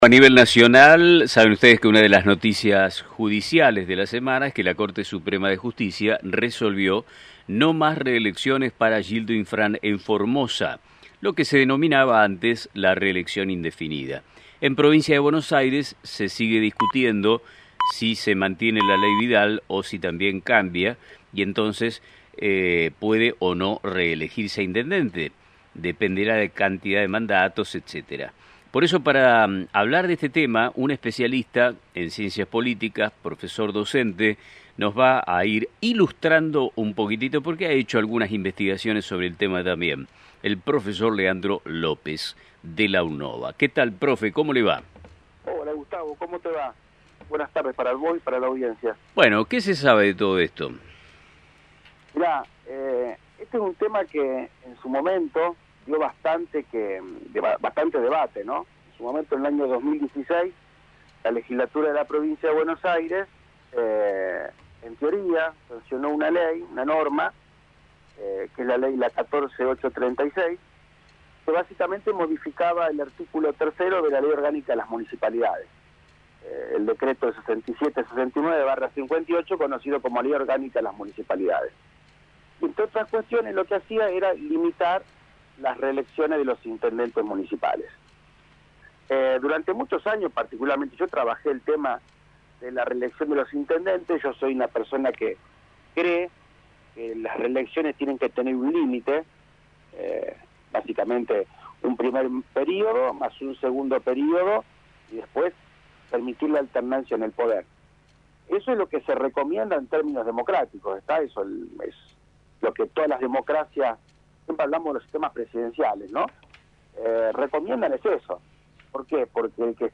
Debate sobre las reelecciones en la Provincia de Buenos Aires: análisis